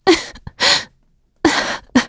Isolet-Vox_Sad_b.wav